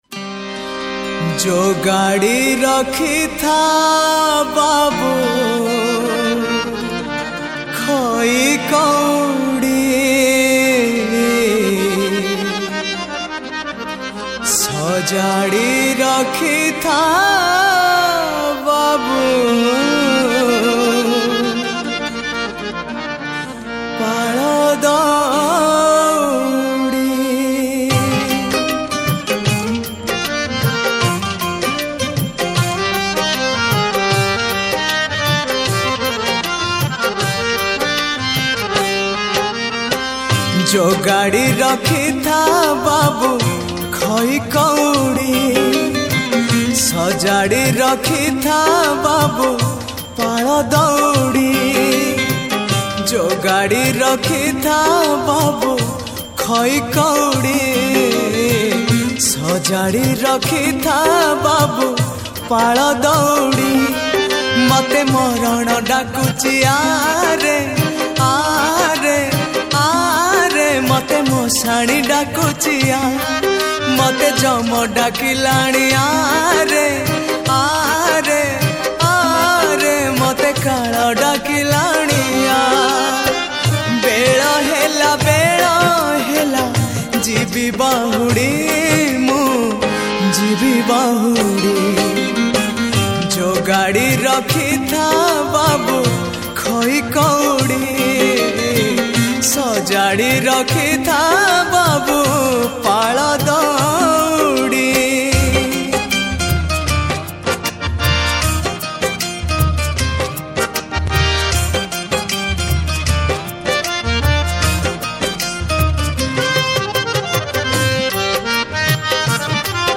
Sad Bhajan Song
Odia Bhajan Songs